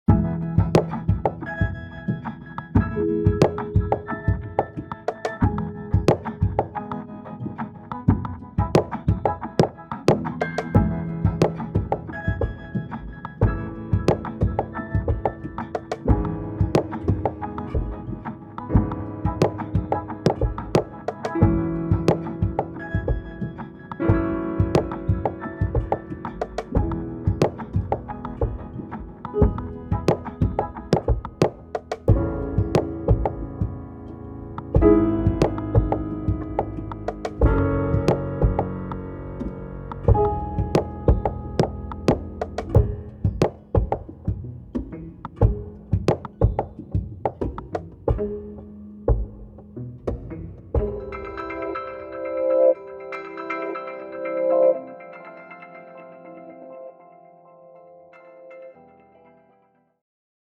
Experimental
a beautiful collection of high-quality piano